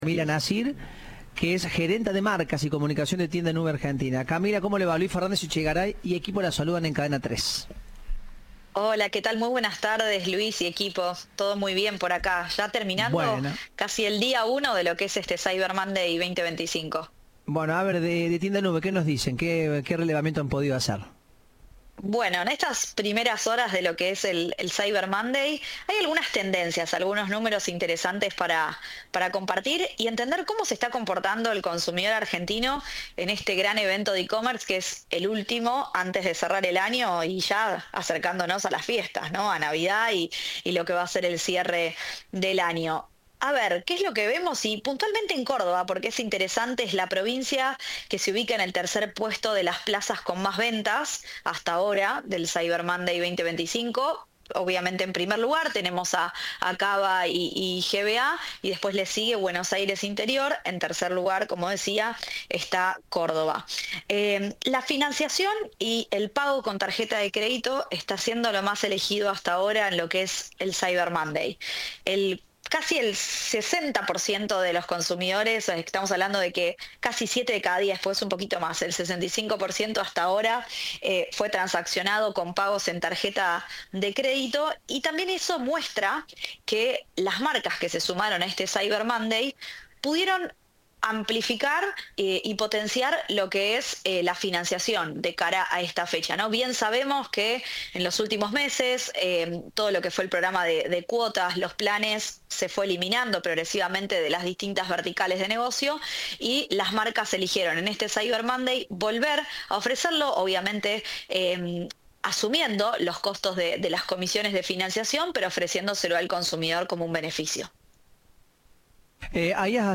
Entrevista de Informados, al Regreso.